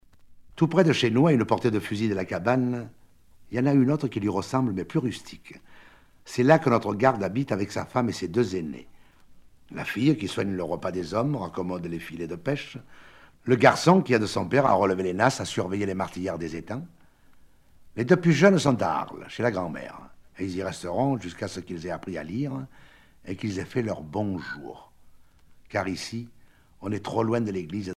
Genre récit